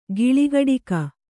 ♪ giḷigaḍika